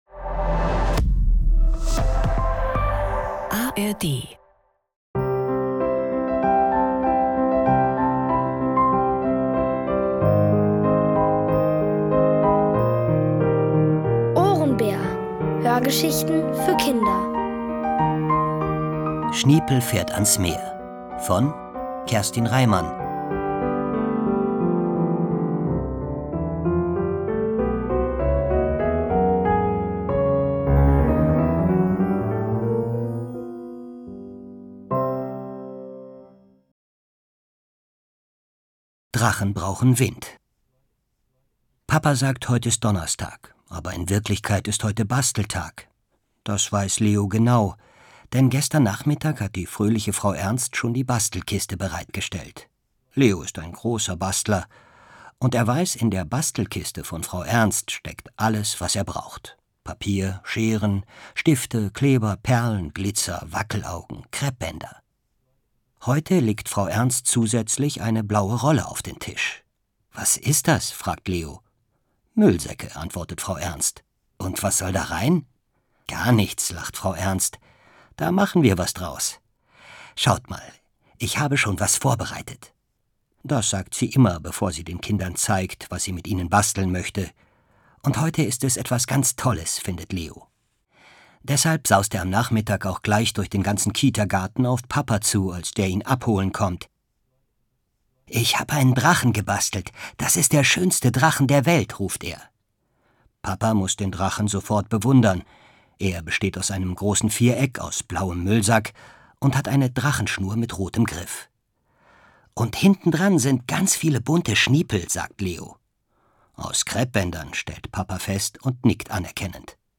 Alle 2 Folgen der OHRENBÄR-Hörgeschichte: Schniepel fährt ans Meer von Kerstin Reimann.